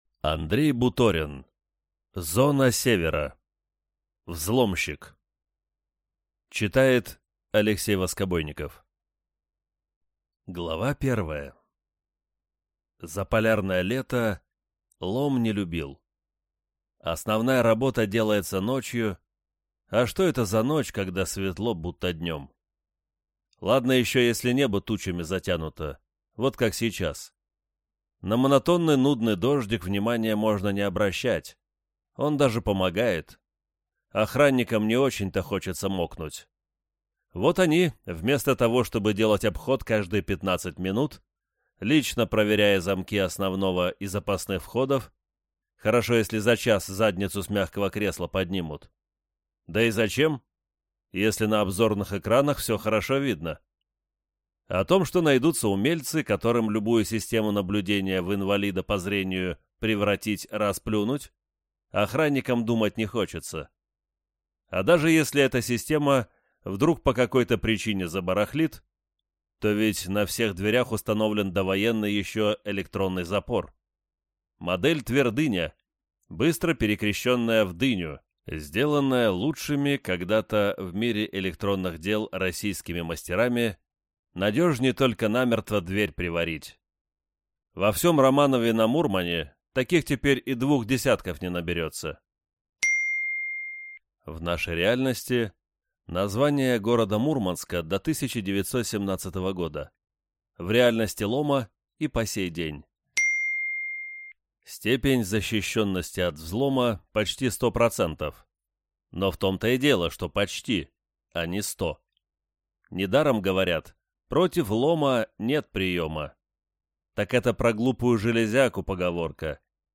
Аудиокнига Зона Севера. Взломщик | Библиотека аудиокниг